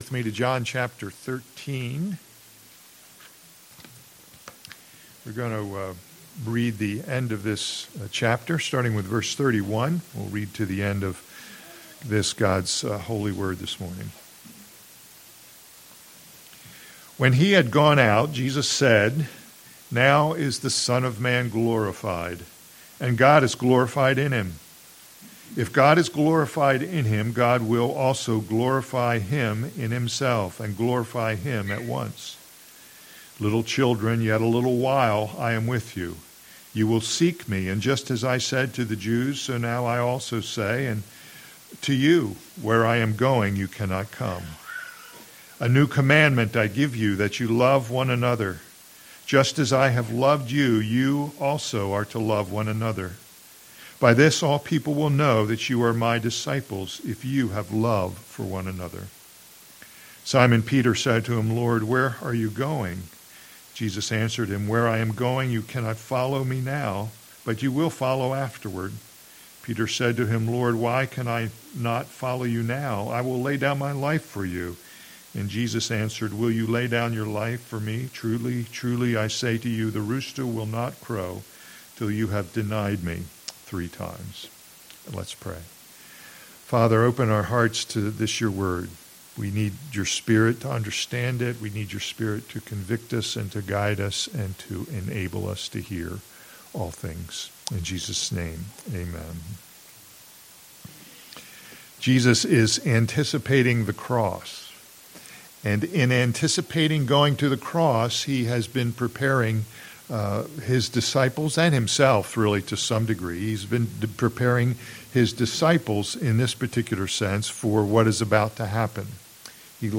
All Sermons Anticipating the Cross